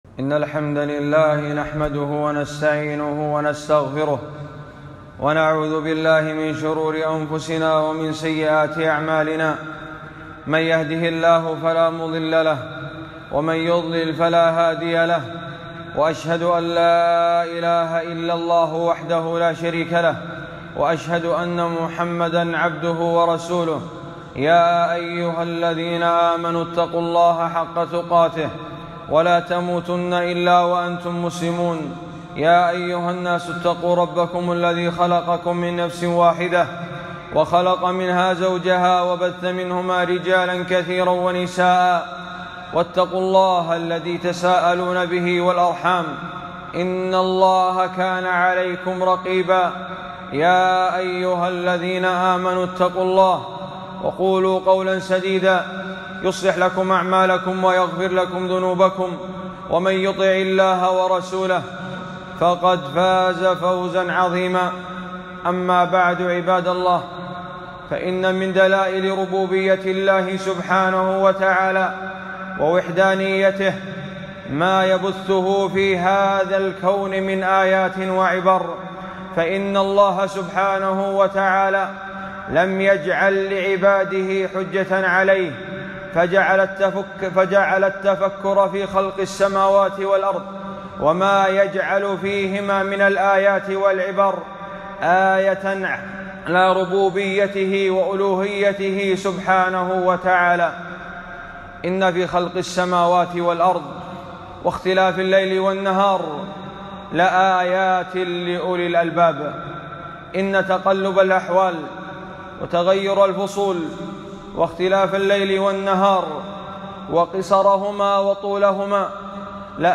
خطبة - الشتاء آياتٌ وعبر